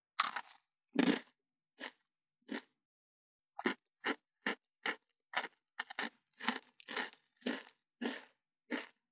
15.スナック菓子・咀嚼音【無料効果音】
ASMR